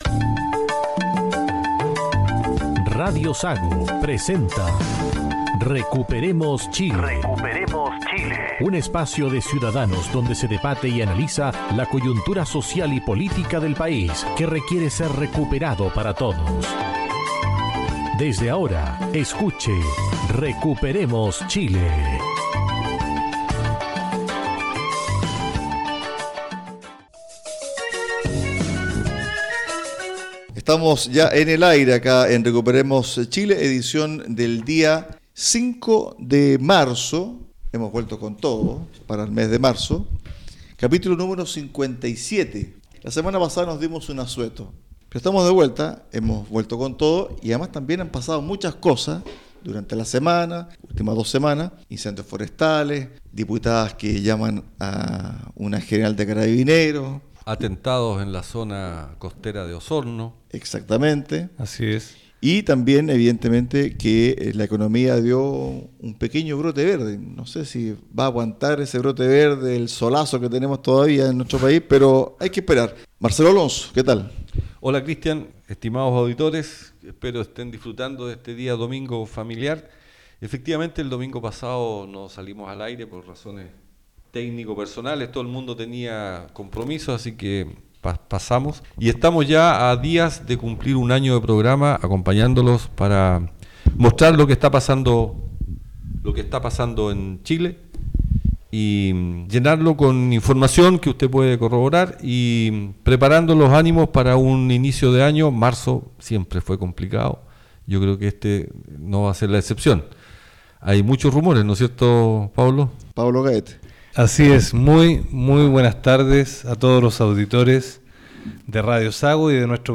En este capítulo los panelistas analizan los cambios de postura constantes de algunos miembros del Gobierno y de los partidos oficialistas, como asimismo la carta de navegación perdida de La Moneda. Además, se hace un balance de los incendios forestales y de cómo las autoridades reaccionaron.